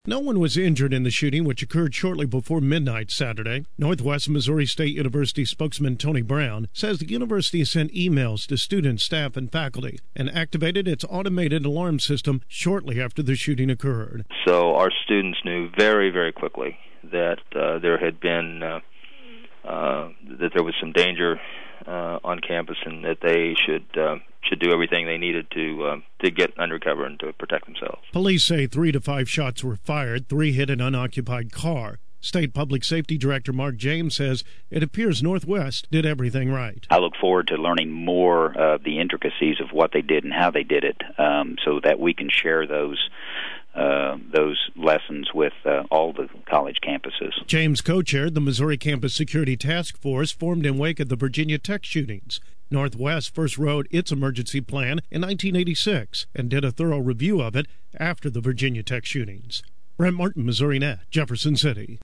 reports